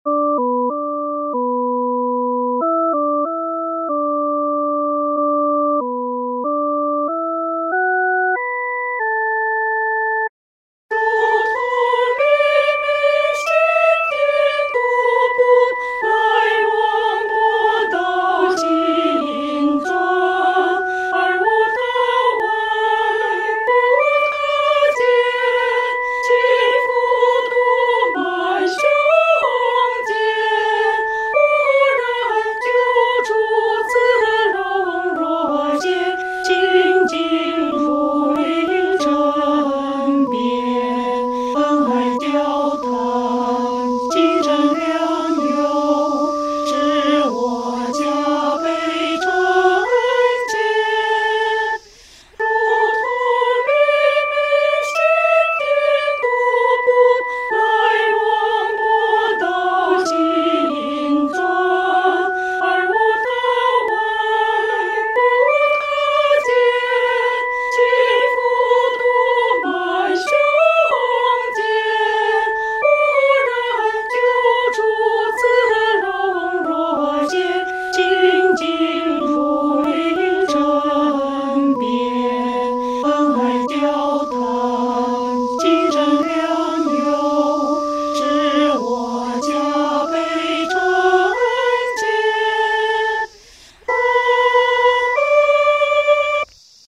女高